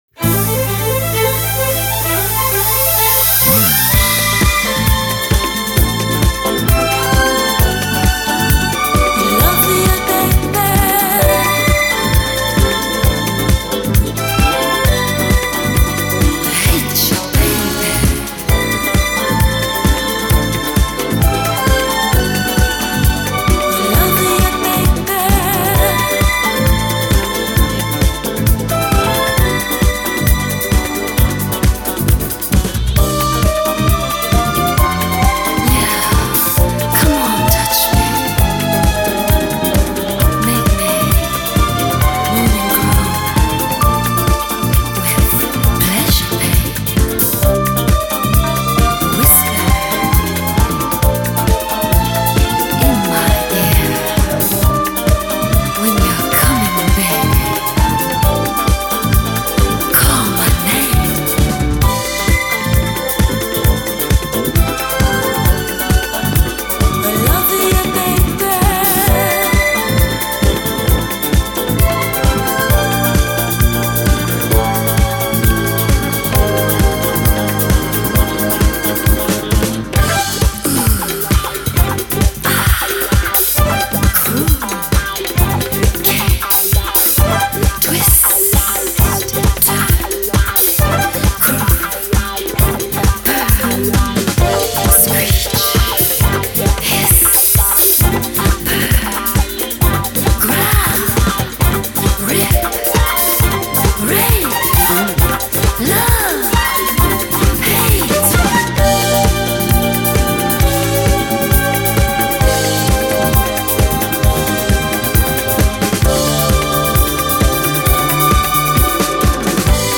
Жанр: Disco